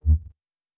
pgs/Assets/Audio/Sci-Fi Sounds/Weapons/Lightsaber 1_3.wav at 7452e70b8c5ad2f7daae623e1a952eb18c9caab4
Lightsaber 1_3.wav